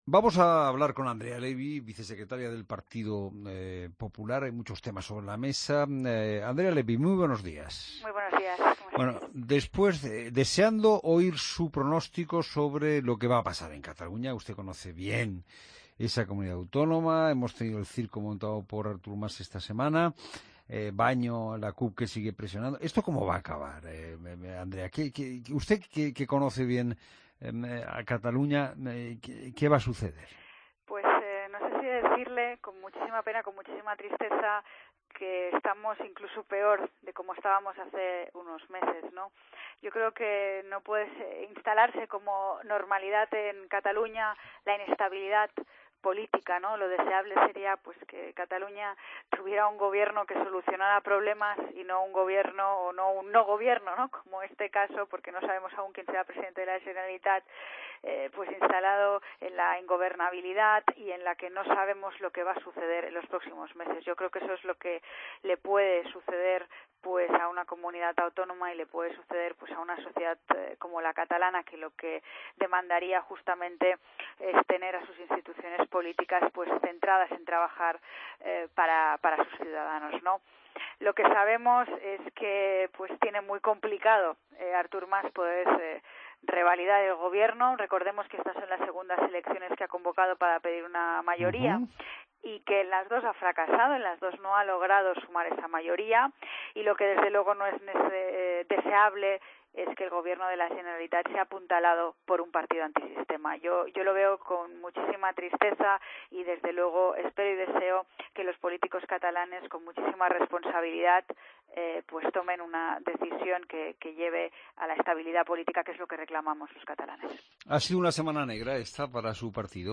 Escucha la entrevista a Andrea Ley en La Mañana Fin de Semana